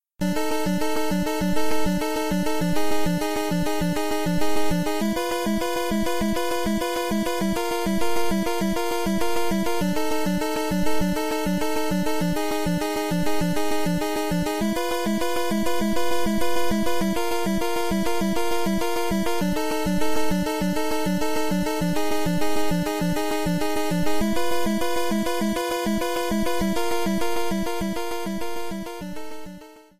Boss theme